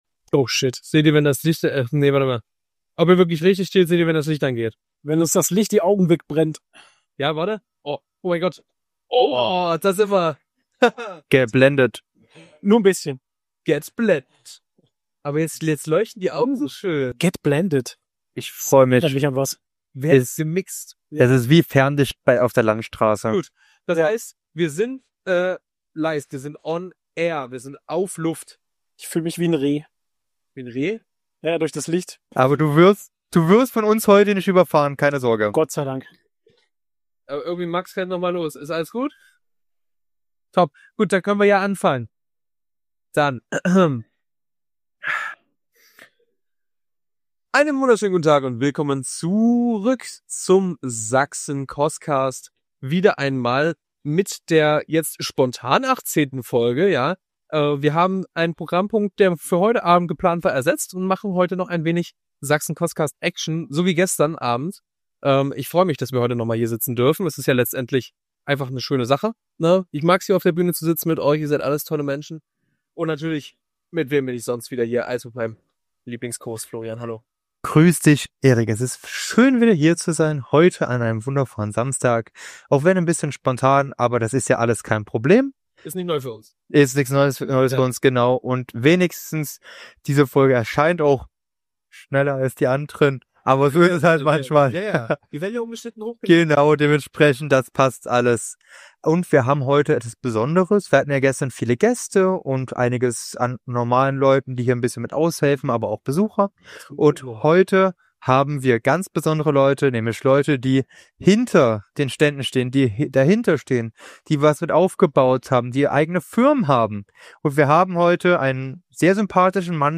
Auch diese Live Episode fand auf der Bühne der Crafting Com 2026 statt, nur diesmal mit dem Schwerpunkt auf den Sponsoren und Partnern der Crafting Com.
In dieser Live Episode reden wir mit ein paar der Sponsoren und Partnern über die Crafting Com an sich, die Zusammenarbeit mit dieser und über die Sponsoren und Partner selber. Diese Folge ist dementsprechend völlig ungeschnitten und dadurch kann es zwischendurch kurze ruhige Passagen geben.